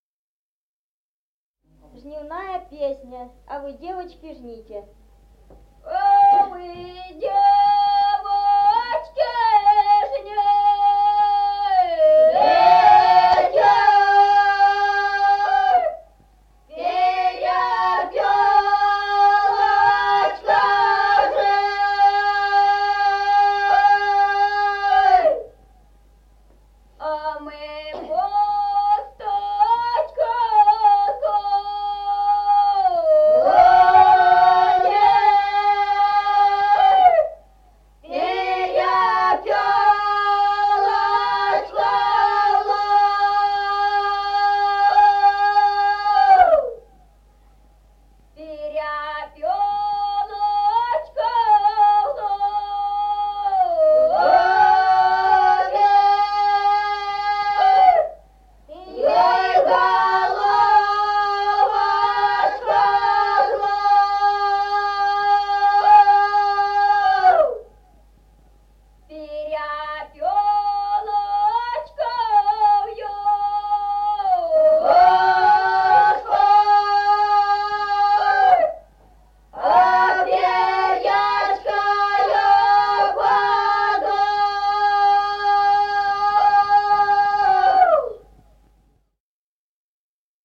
Народные песни Стародубского района «А вы, девочки, жните», жнивные.
(подголосник)
(запев).
1953 г., с. Мишковка.